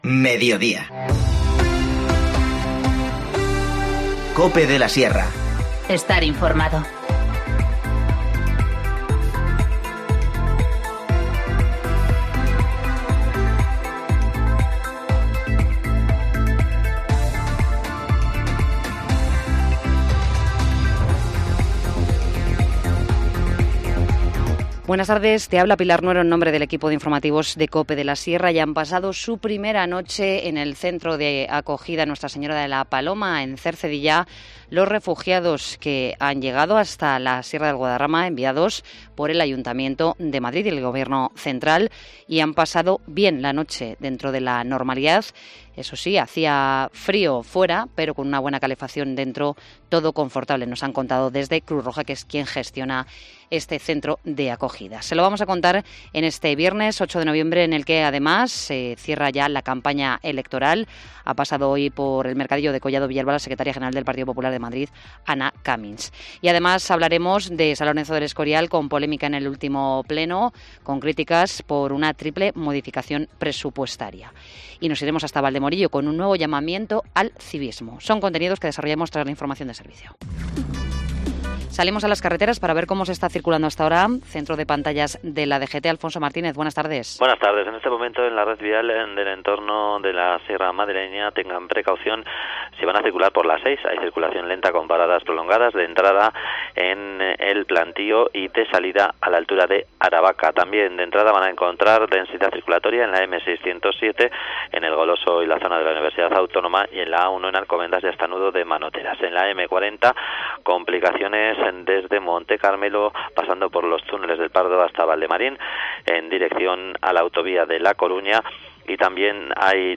Informativo Mediodía 8 noviembre 14:20h